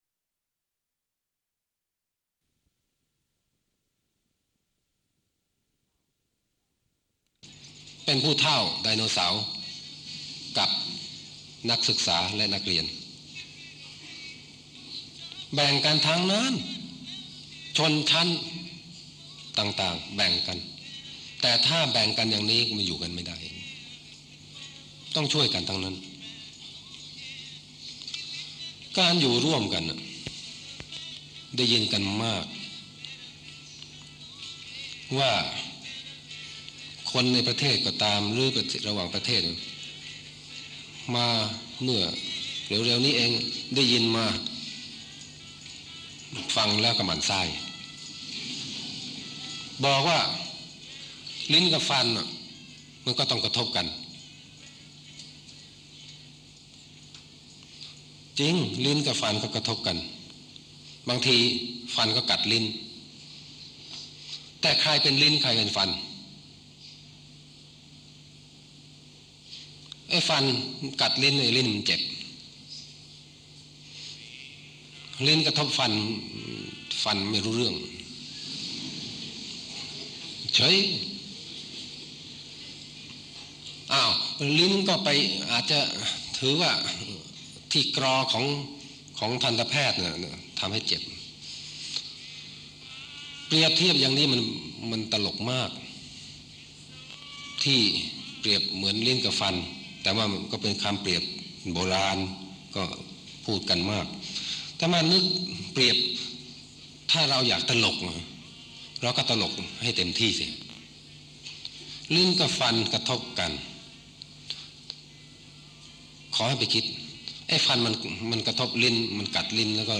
พระบรมราโชวาทของพระบาทสมเด็จพระเจ้าอยู่หัว 5 ธันวาคม 2518